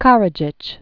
(kärə-jĭch), Radovan Born 1945.